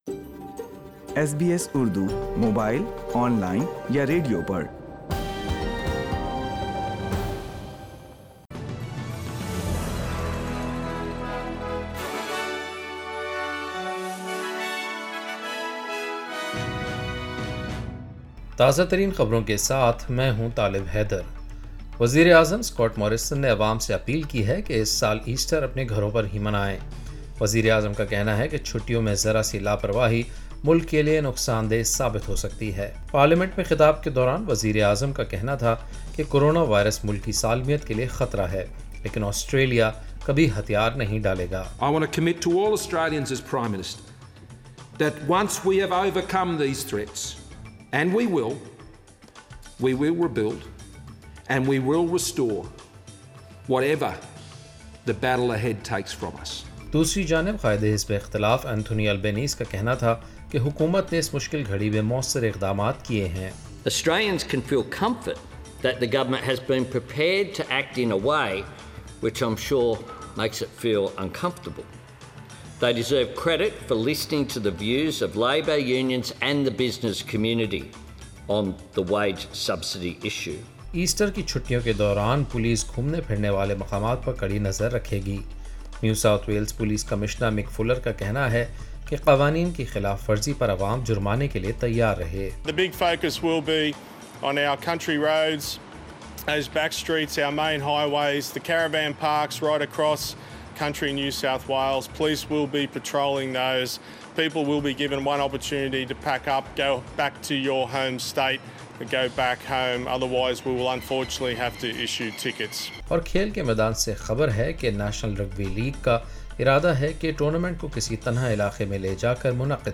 daily_news_8.4.20_4pm.mp3